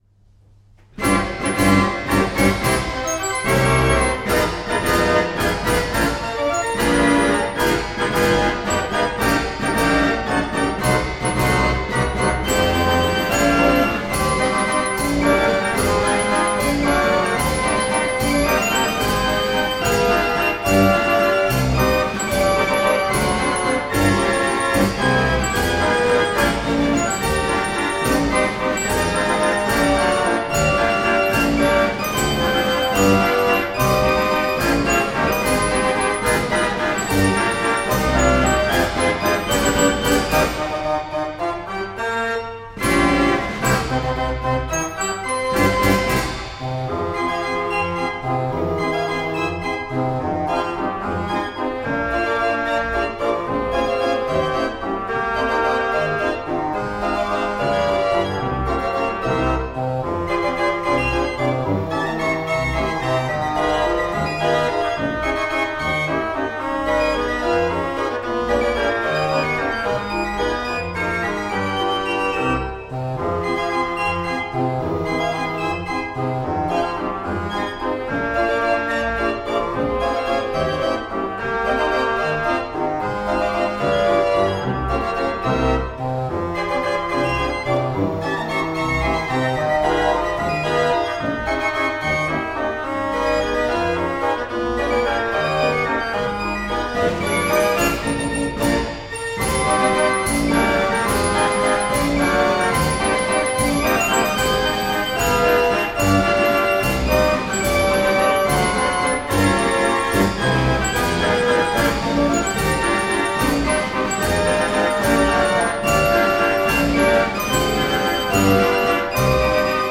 Historisches Drehorgeltreffen in Lichtensteig (SG)
Konzertorgel „De Lange Gavioli“
Posaune   ................................... 16'